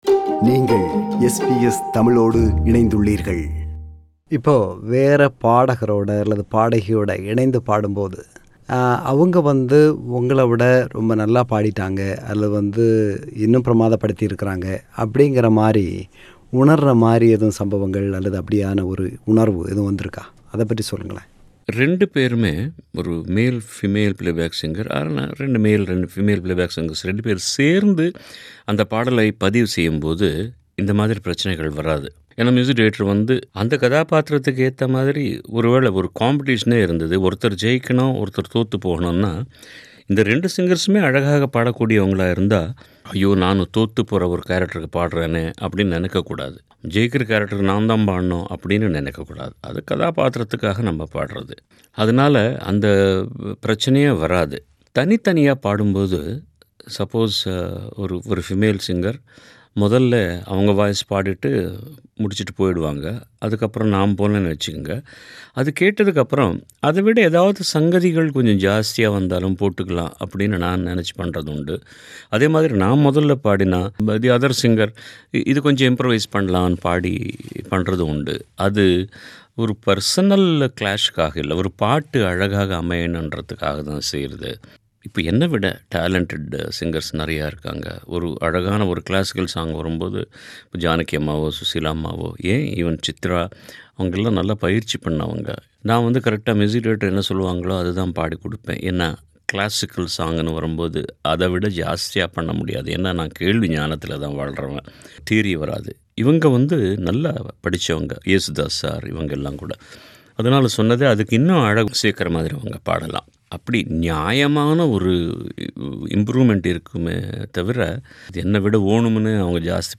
மறைந்த பிரபல பின்னணிப்பாடகர் S P பாலசுப்ரமணியம் அவர்கள் SBS தமிழ் ஒலிபரப்புக்கு சுமார் ஆறு ஆண்டுகளுக்கு முன்பு வழங்கிய நேர்முகத்தின் மறுபதிவு.